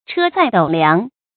注音：ㄔㄜ ㄗㄞˋ ㄉㄡˇ ㄌㄧㄤˊ
車載斗量的讀法